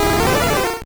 Cri de Rapasdepic dans Pokémon Or et Argent.